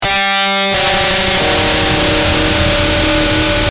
Screamb6.wav